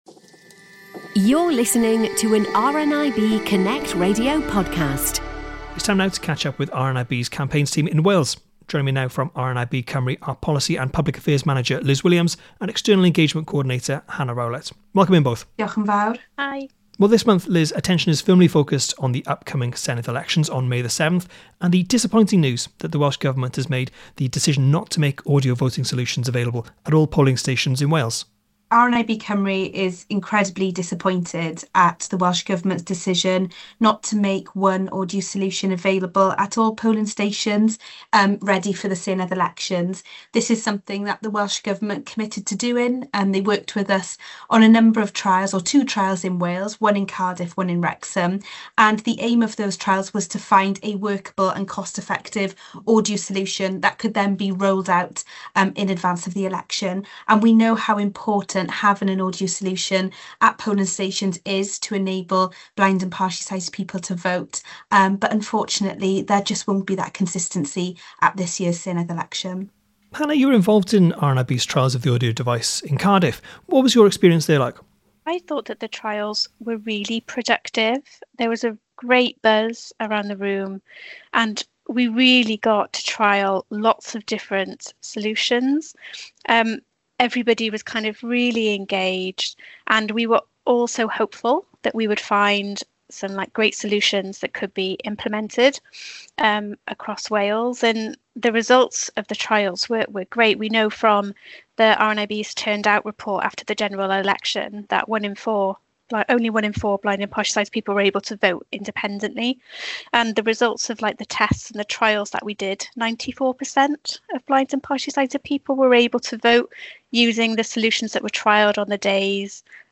Each month RNIB Connect Radio speaks to the Policy and Campaigns Team from RNIB Cymru. This month, how the Welsh Government has turned down a proposal that could impact the way blind and partially sighted people cast their vote in the upcoming Senedd elections.